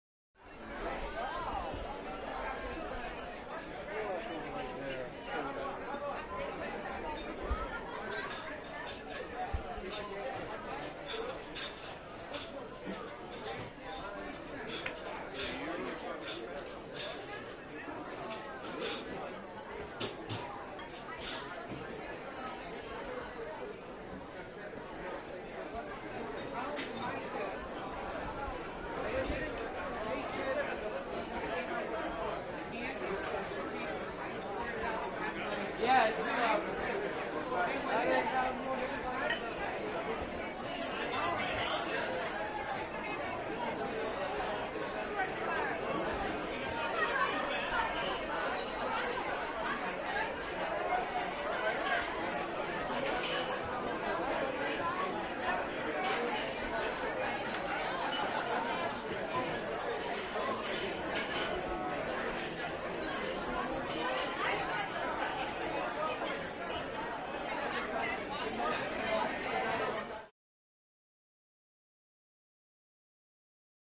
Restaurant - Busy With Dishes & Silverware